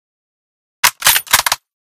bolt_layer.ogg